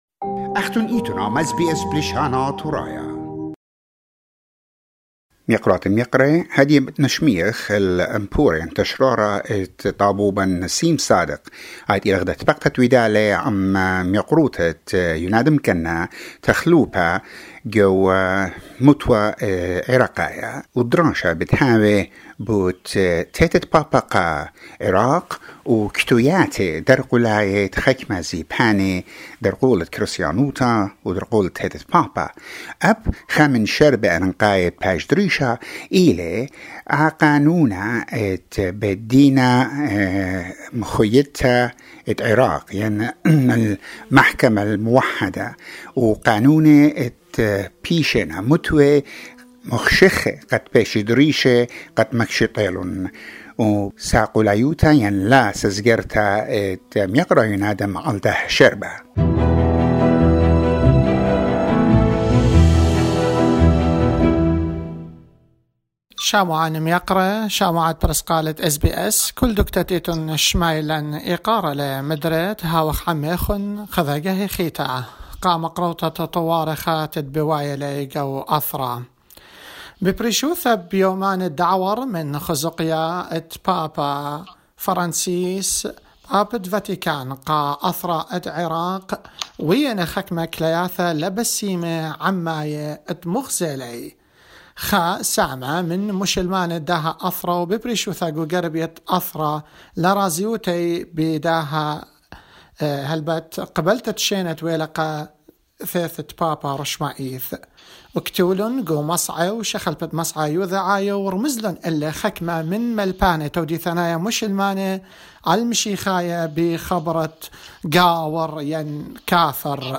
interviewed the Hon. Younadam Kanna, Secretary general of Assyrian Democratic Movement and member of the Iraqi Parliament